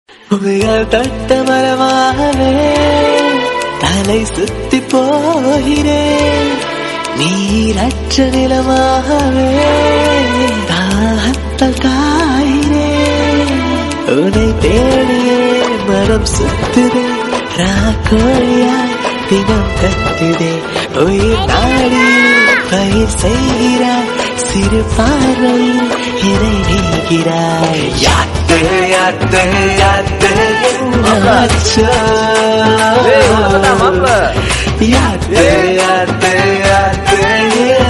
Best Ringtones, Tamil Ringtones